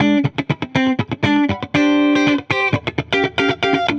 Index of /musicradar/dusty-funk-samples/Guitar/120bpm
DF_70sStrat_120-D.wav